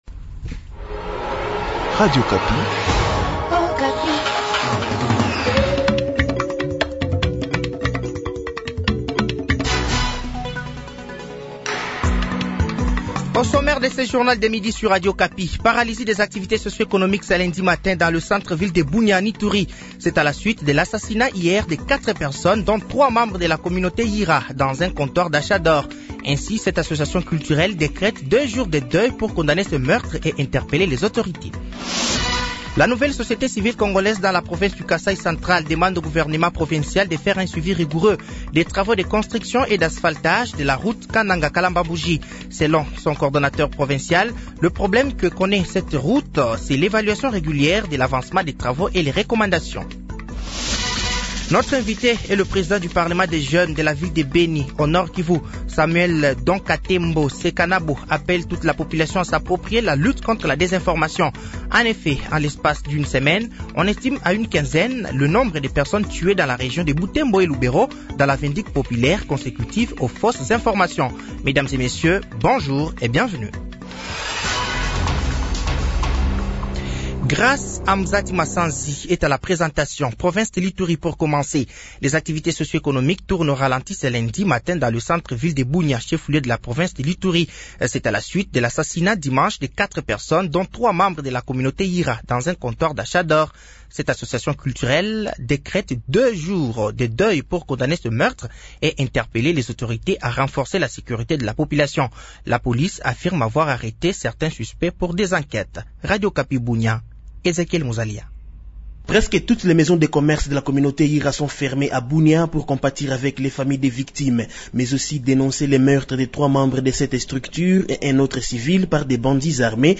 Journal français de 12h de ce lundi 08 juillet 2024